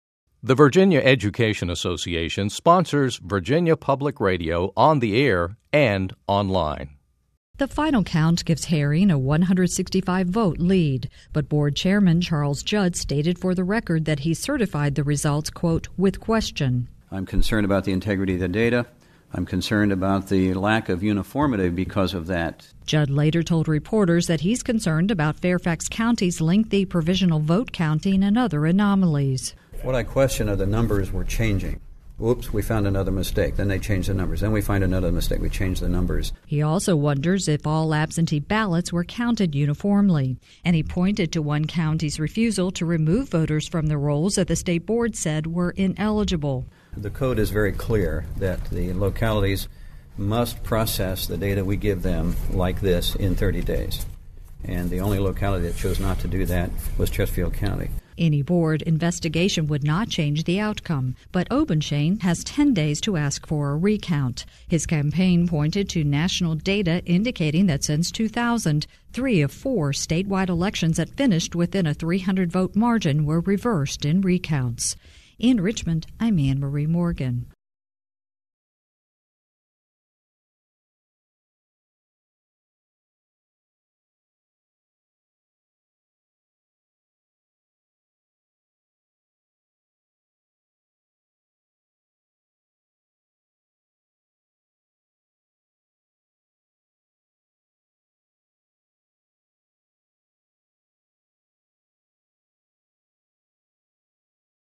Virginia Public Radio’s
reports